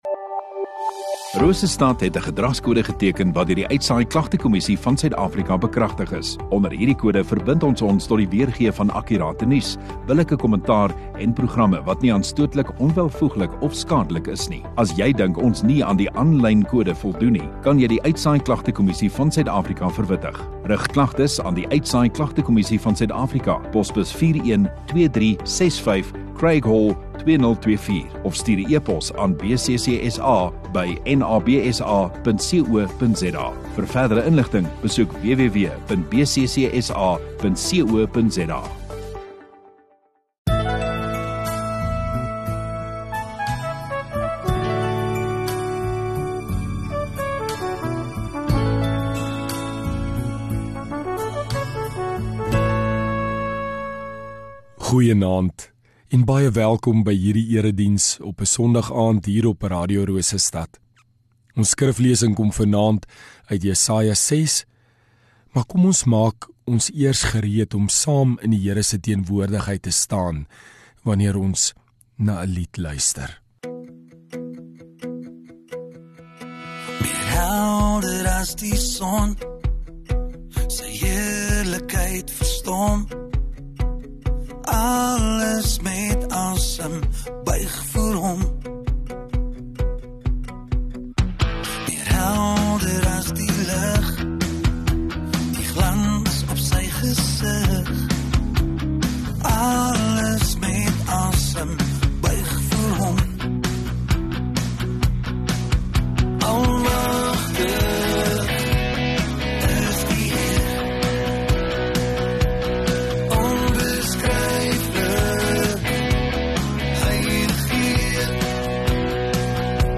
14 Jul Sondagaand Erediens